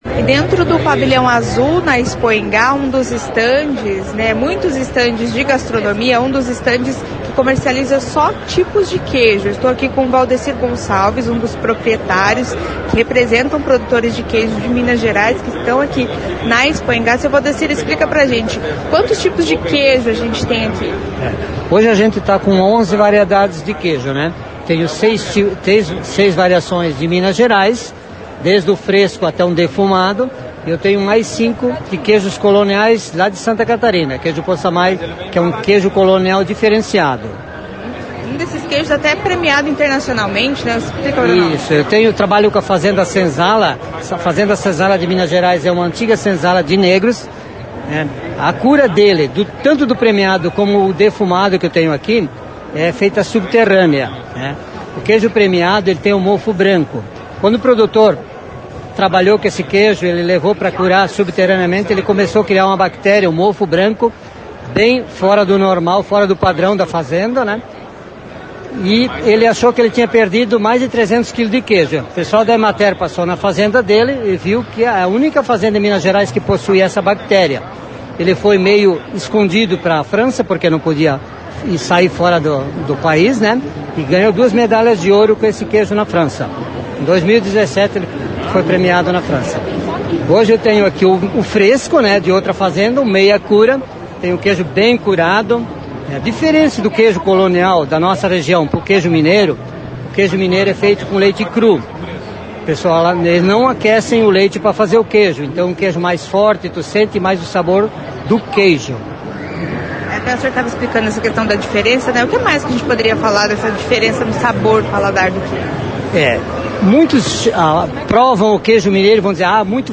Como degustar melhor cada tipo de queijo? A reportagem conversou com especialista no estande.